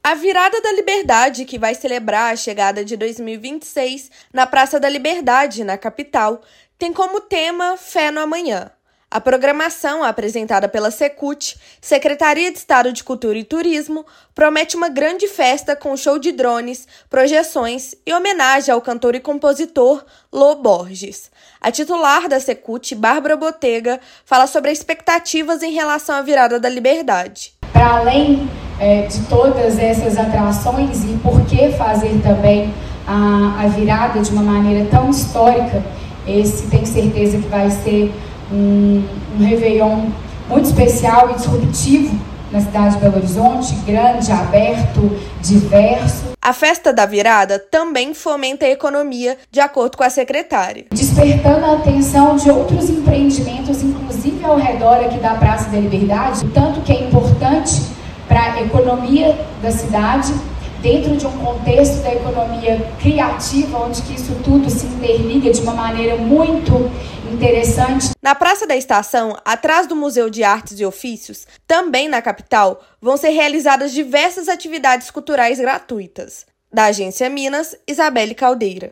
[RÁDIO] Virada da Liberdade 2026 terá grande palco cenográfico e show inédito de drones
Celebração dá as boas-vindas a 2026 e posiciona Minas Gerais entre os principais destinos de fim de ano do país. Ouça matéria de rádio.